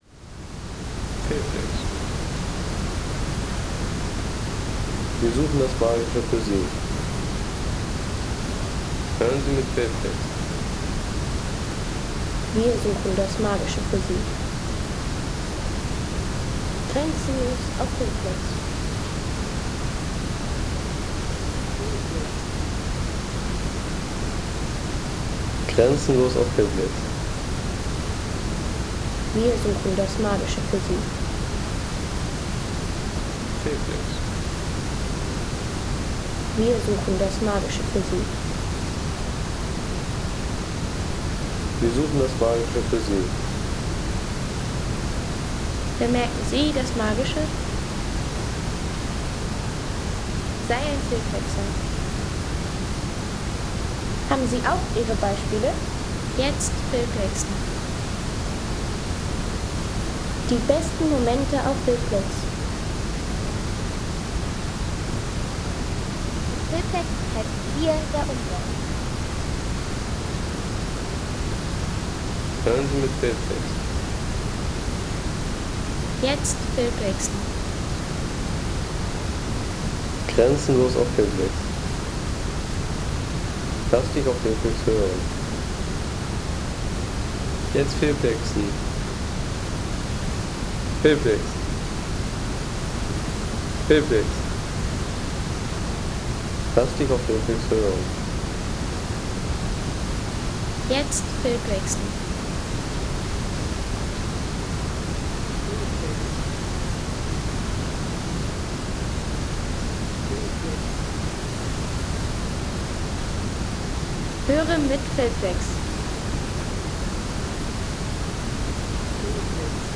Der tosende Zwillingswasserfall von Odda
Landschaft - Wasserfälle